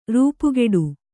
♪ rūpugeḍu